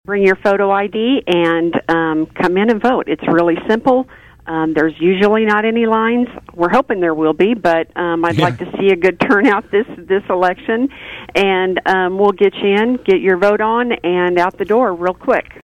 KVOE’s Monday Newsmaker guest, Lyon County Clerk and Election Officer Tammy Vopat, says it’s a simple process to handle advance voting in person.